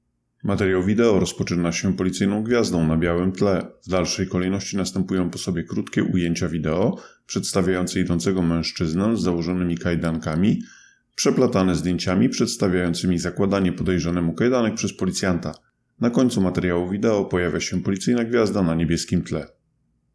Nagranie audio Audio deskrypcja do materiału wideo.